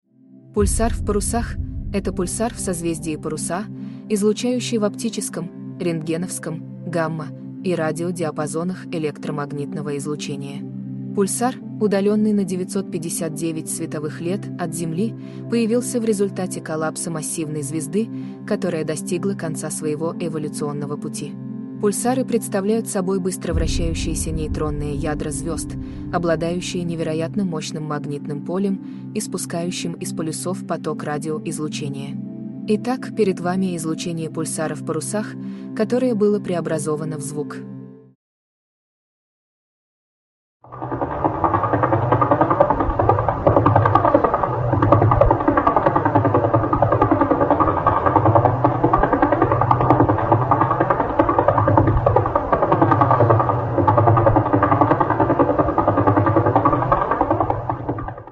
КАК ЗВУЧИТ ПУЛЬСАР В ПАРУСАХ sound effects free download